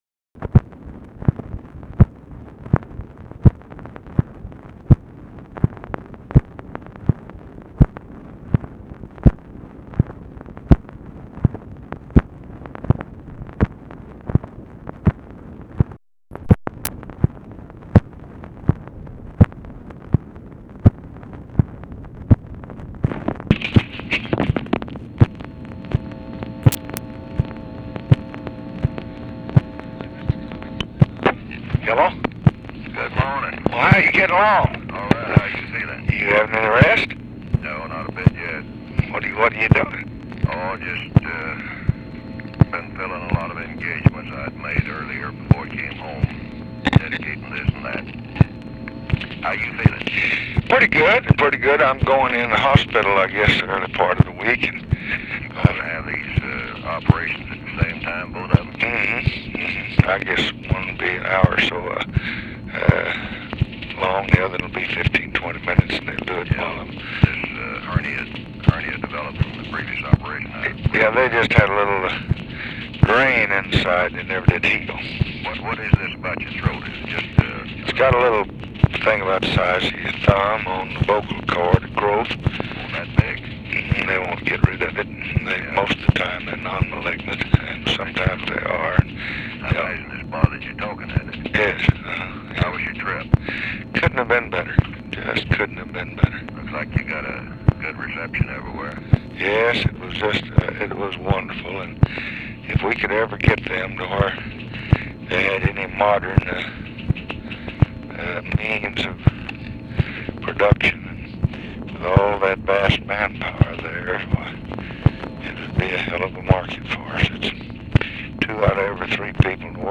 Conversation with WILBUR MILLS, November 12, 1966
Secret White House Tapes